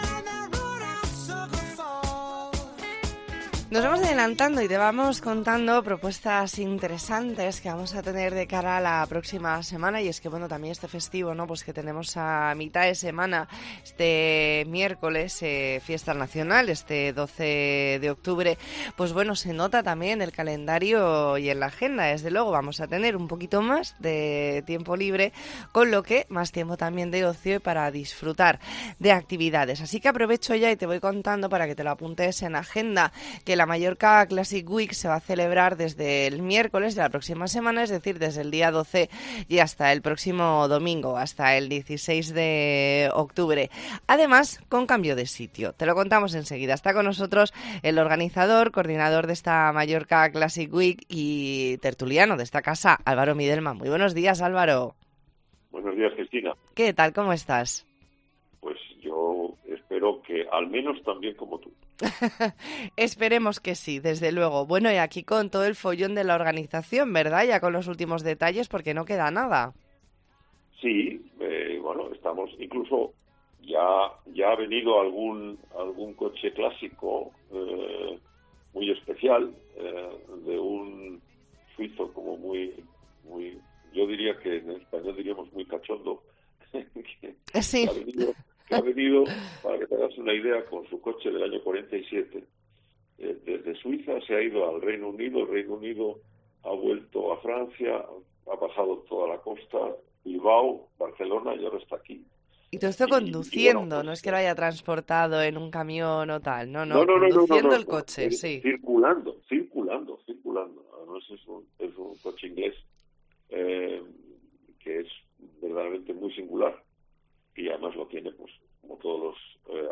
ntrevista en La Mañana en COPE Más Mallorca, viernes 7 de octubre de 2022.